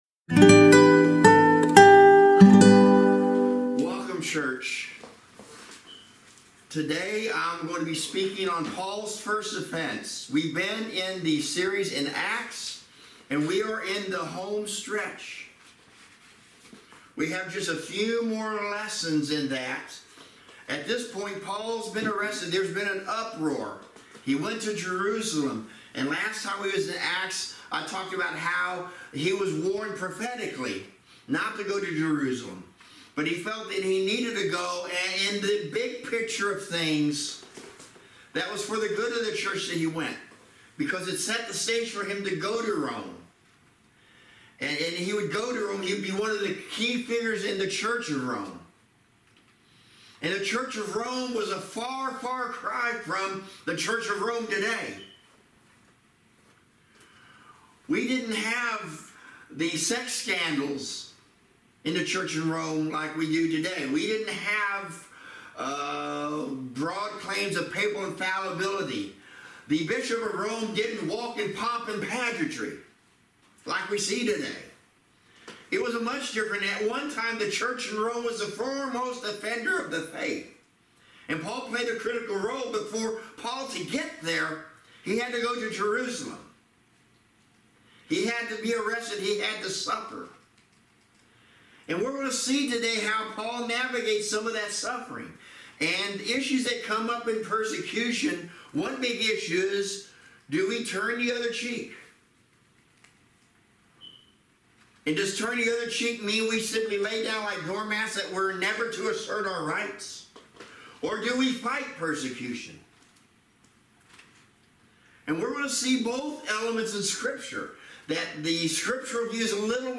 Thirsty Thursday Midweek Teaching